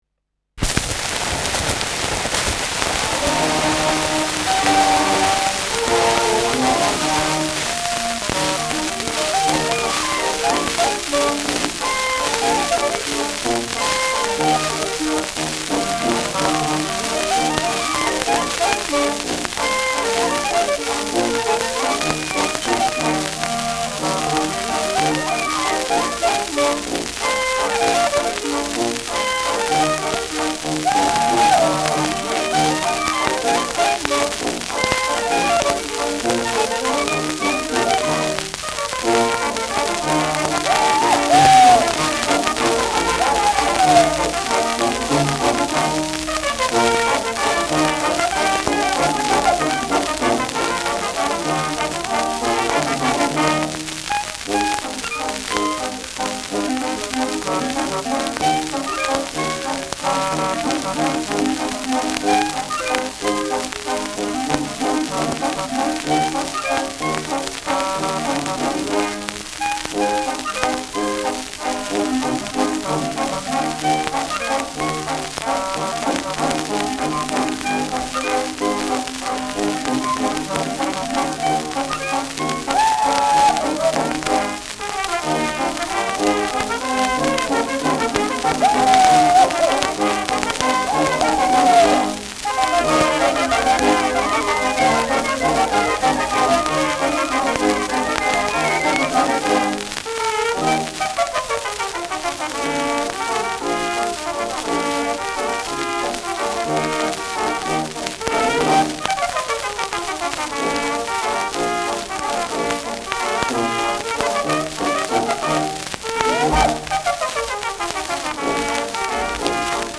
Schellackplatte
starkes Rauschen : abgespielt : leiert : präsentes Nadelgeräusch : gelegentliches Knacken : präsentes bis starkes Knistern
Kapelle Lang, Nürnberg (Interpretation)
Mit Juchzern.
[Nürnberg] (Aufnahmeort)